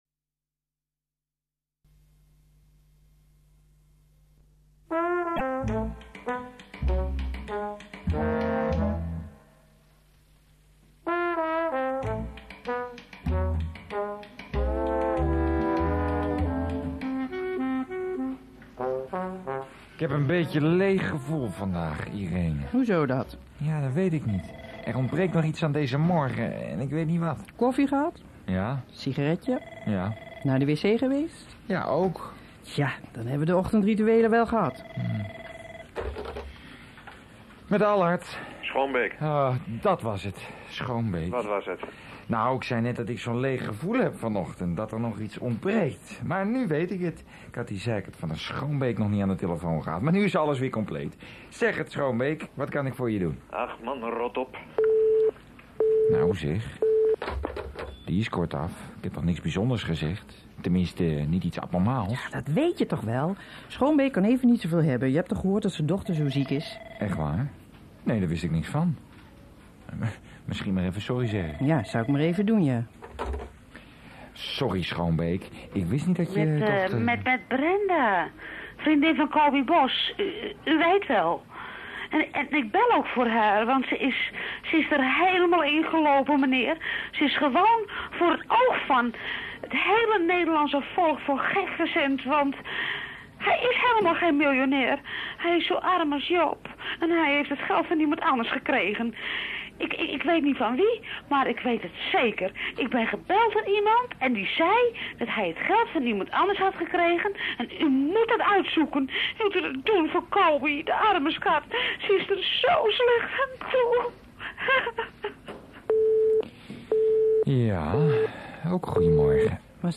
Deze hoorspelserie bestaat uit 15 afzonderlijke delen.